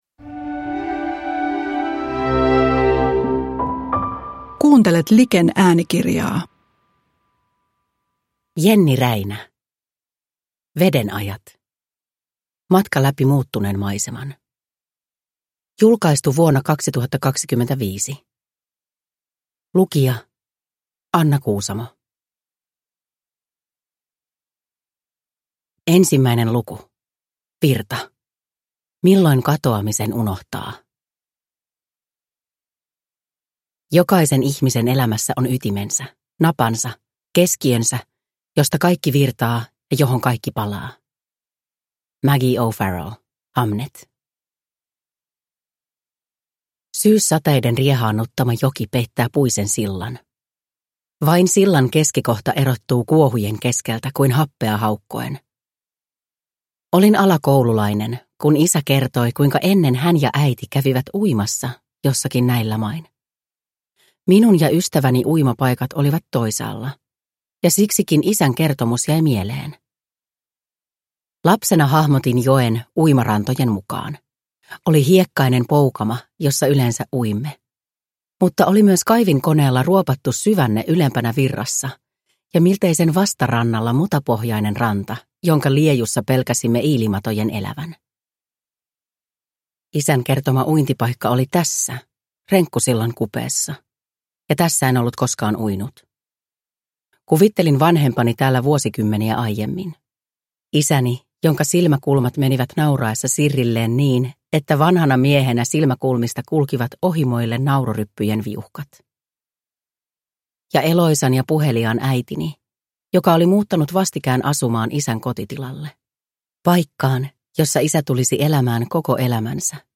Veden ajat – Ljudbok